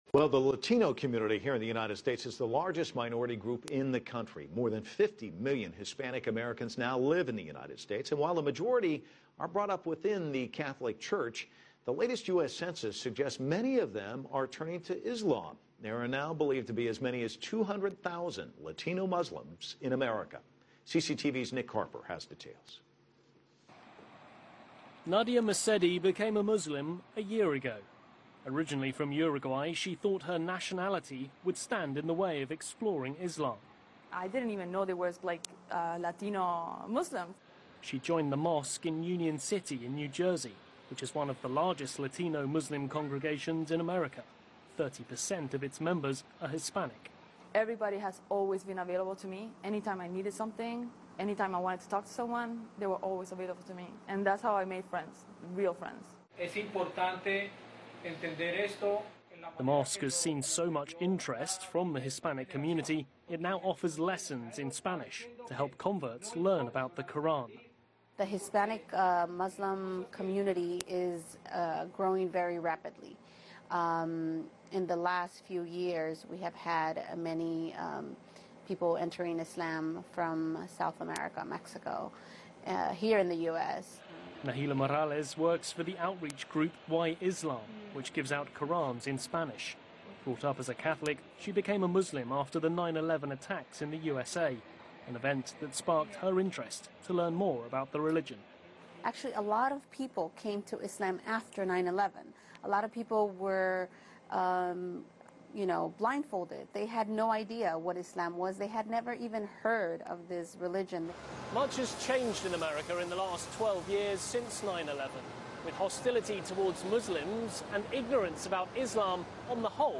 This interview was arranged by WhyIslam. Find out why so many Latinos are exploring Islam as the way of life.